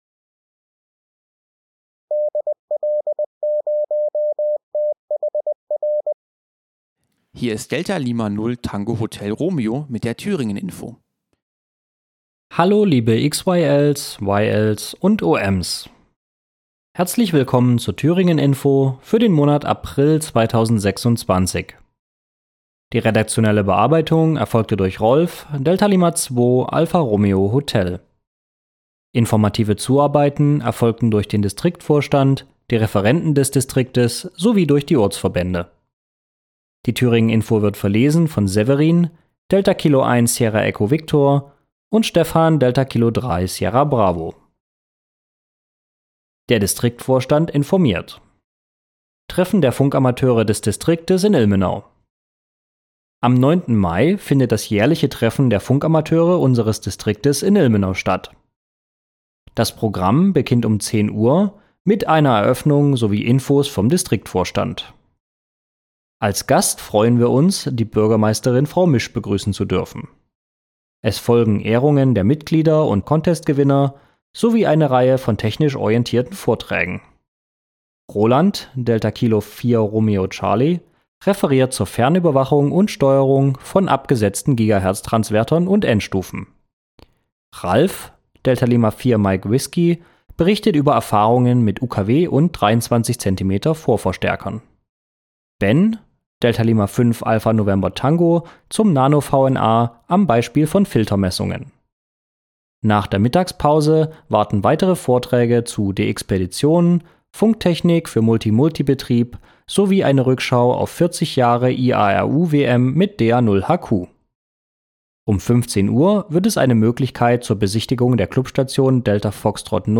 Die Thüringen-Info ist ein Infoblatt des Distriktsvorstand Thüringen zu aktuellen Themen in und um unseren Distrikt und erscheint jeweils am 3. Samstag des Monats.